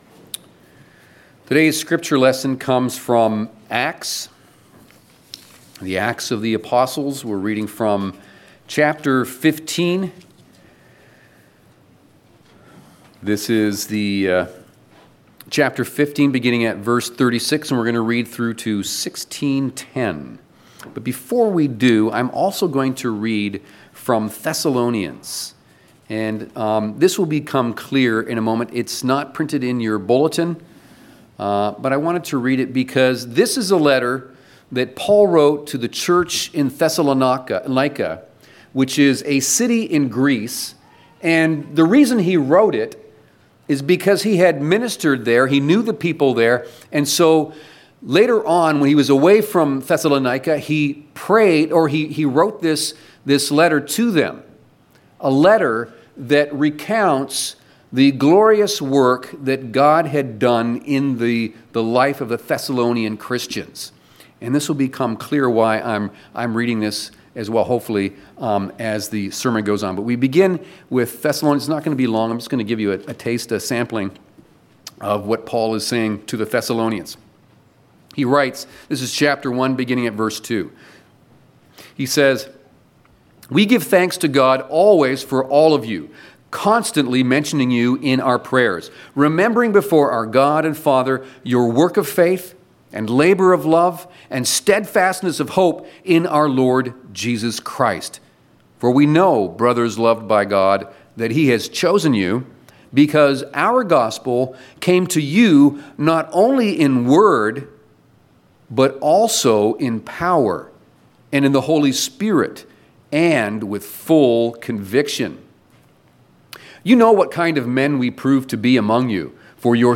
5.sermon-_-New-Frontiers.mp3